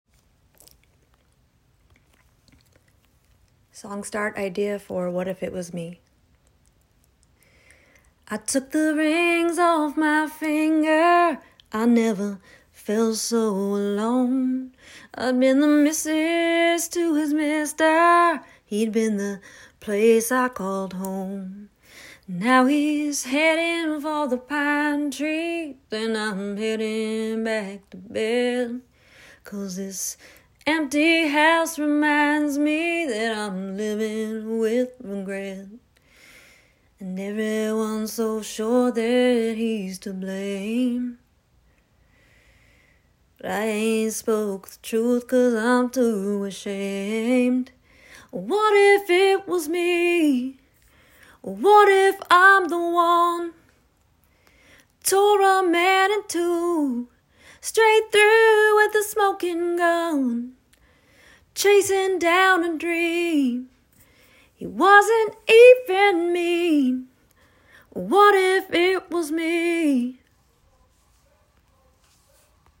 Voice Memo Song Starter